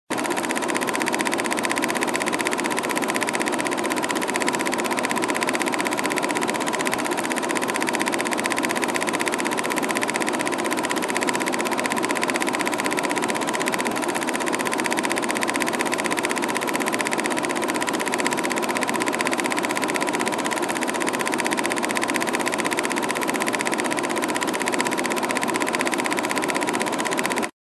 На этой странице собраны звуки кинопроектора в высоком качестве – от мягкого гула до характерных щелчков пленки.
Звук старого пленочного кинопроектора